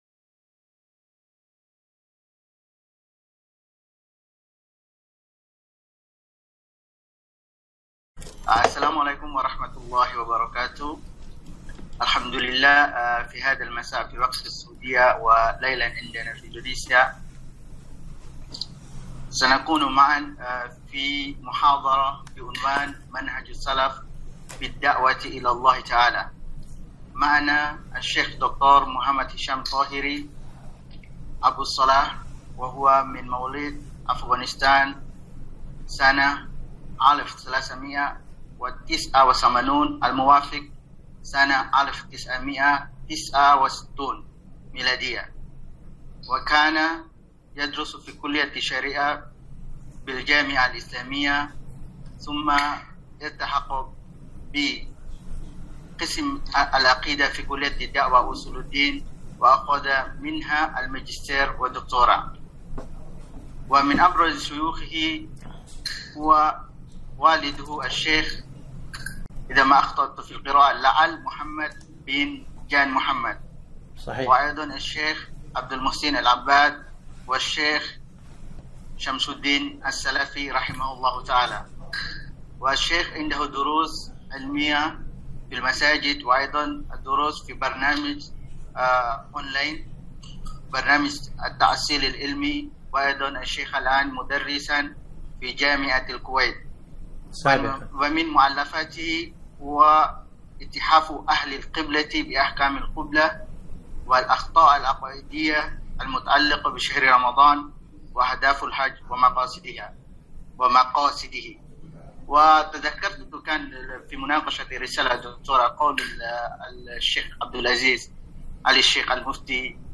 محاضرة - منهج السلف في الدعوة إلى الله